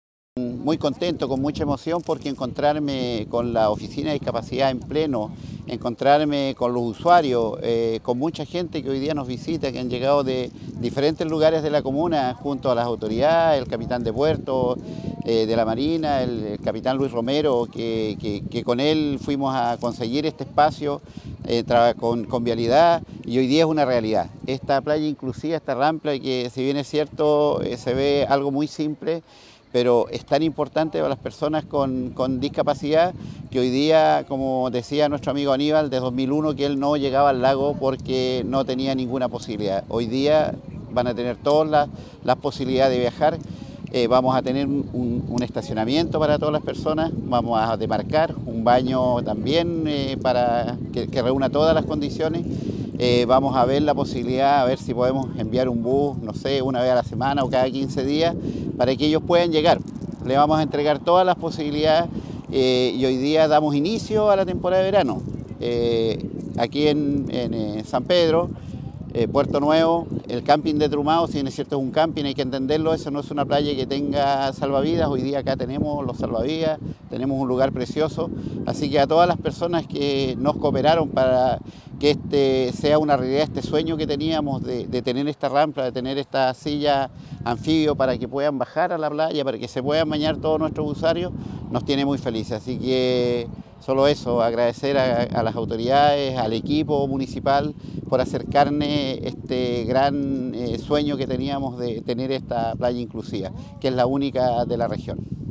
ENTREVISTA-ALCALDE-RAMPA.mp3